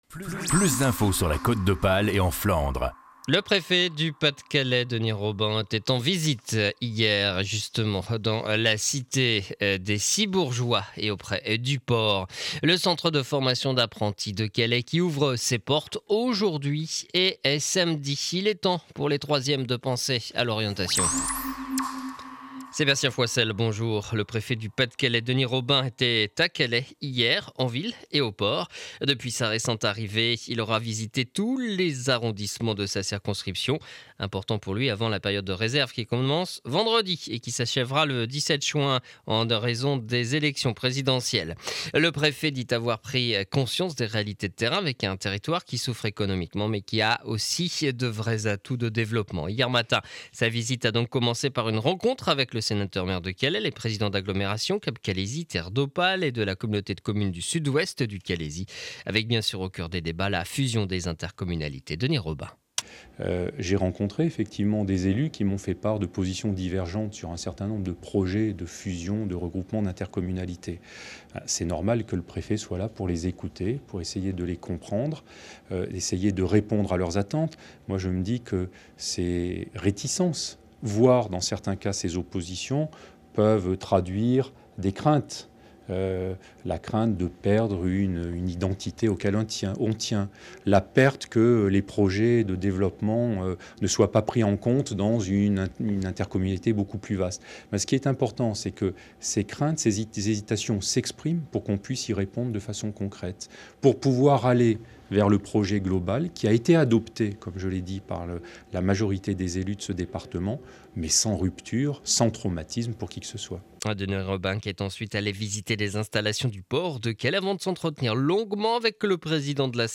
Journal du jeudi 20 mars 2012 7 heures 30 édition du Calaisis.